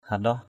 /ha-ɗɔh/